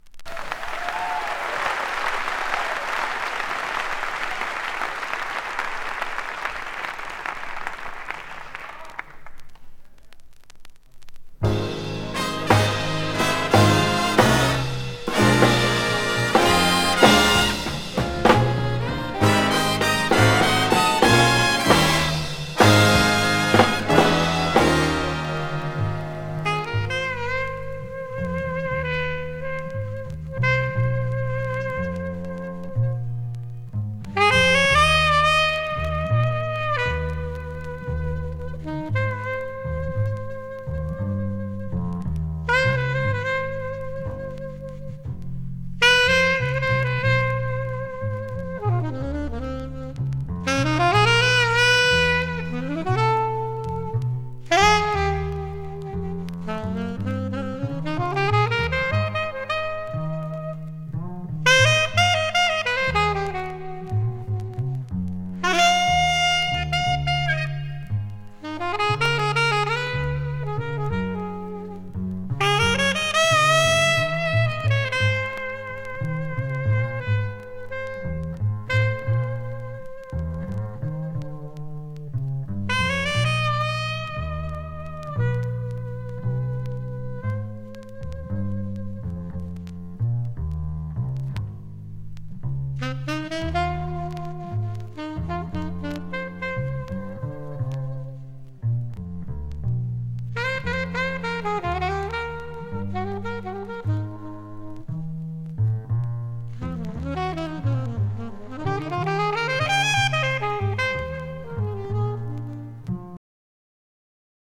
75年5月に行われたジャズフェスのライブ音源。
盤質：B+（試聴程度のチリつくとこあり） ジャケット：縁・角軽度スレ、帯付き、冊子あり。